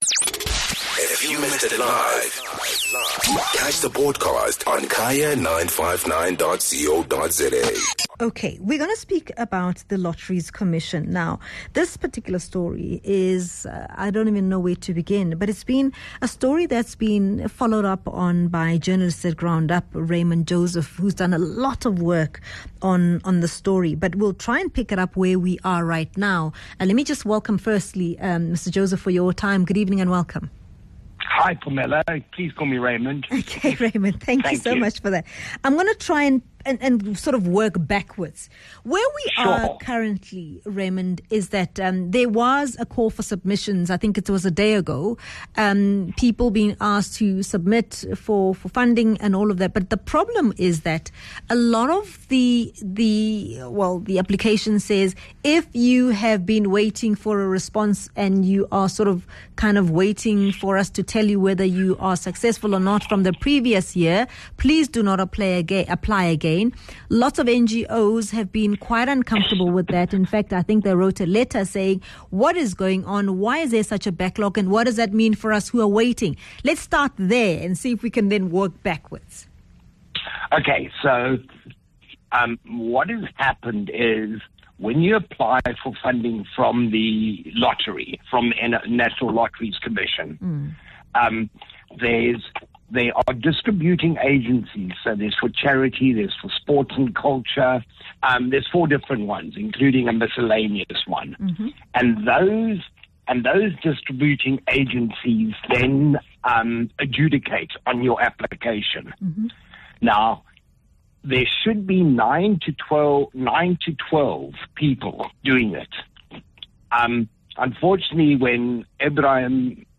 We also speak to the Chairperson of the Porfolio Committee on Trade and Industry, Mzwandile Masina.